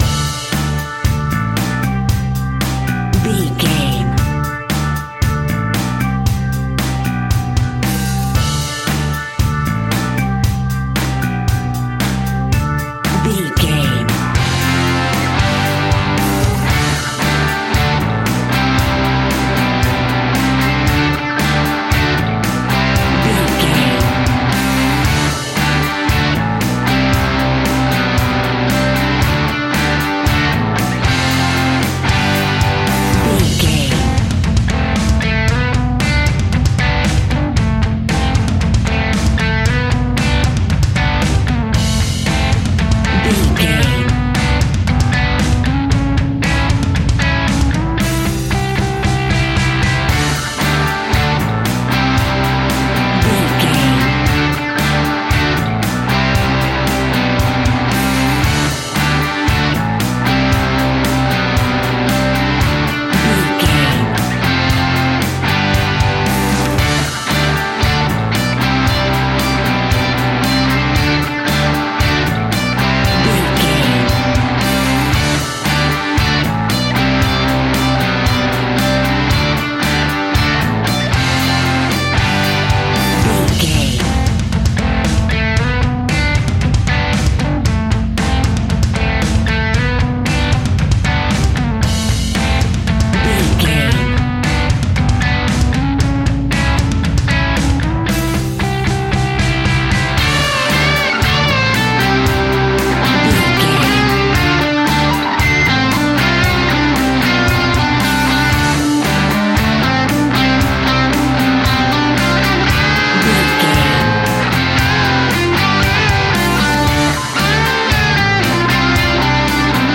AC Rock.
Epic / Action
Fast paced
Ionian/Major
heavy metal
heavy rock
blues rock
distortion
hard rock
Instrumental rock
drums
bass guitar
electric guitar
piano
hammond organ